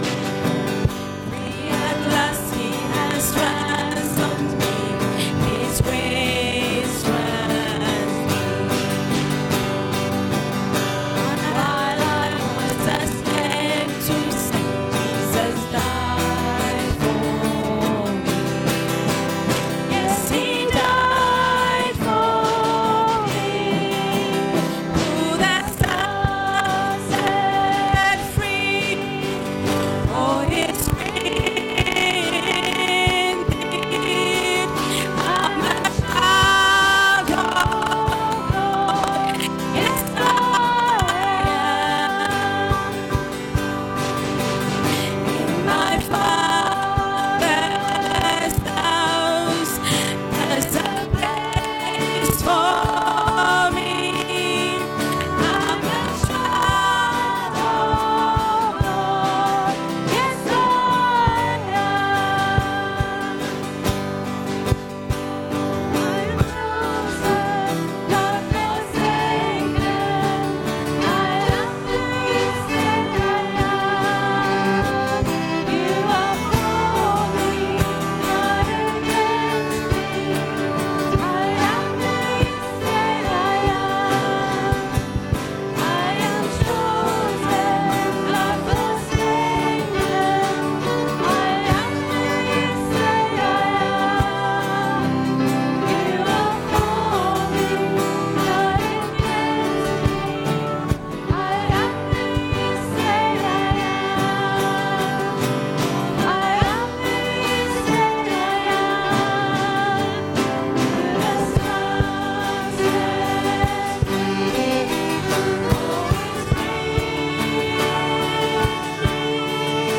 Come and hear the third in our sermon series ‘The Jesus Life.’
Service Audio